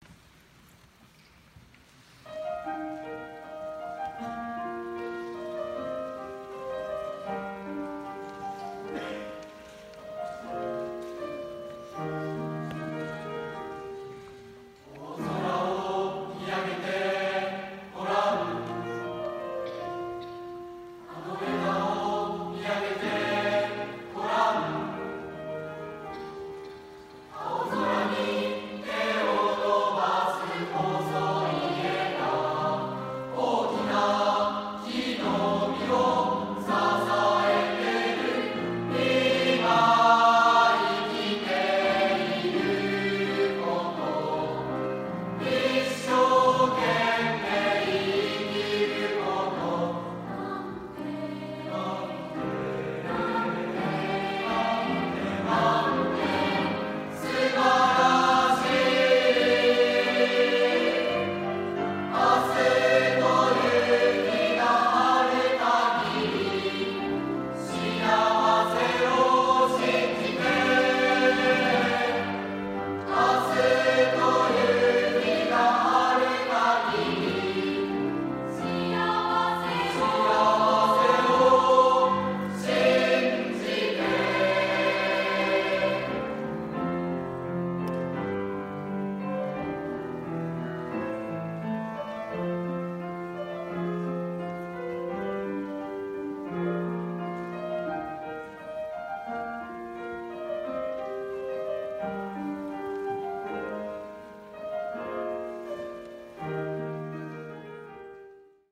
平成２９年度宇城中学校音楽会での合唱
平成２９年１１月１７日（金）、宇城市ウイング松橋にて、「平成２９年度宇城中学校音楽会」が行われました。 本校からは、3年生合唱と吹奏楽が参加しました。